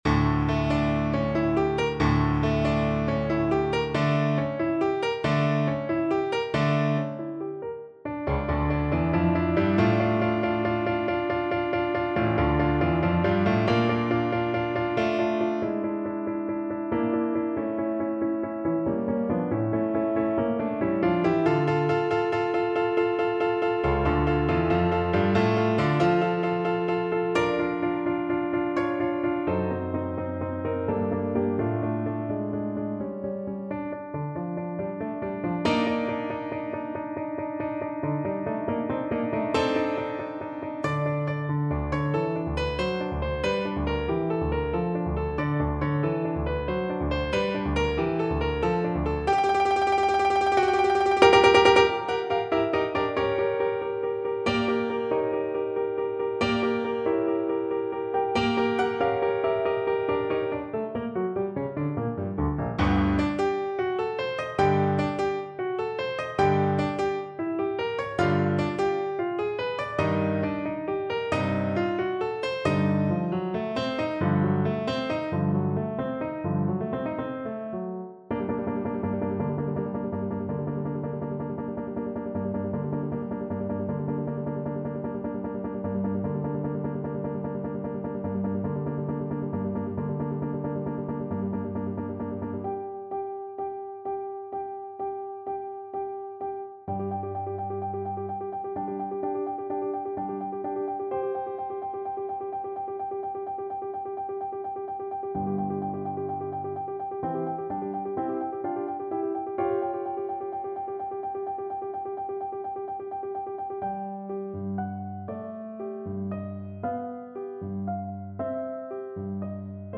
9/8 (View more 9/8 Music)
Schnell und wild = 185
Classical (View more Classical Voice Music)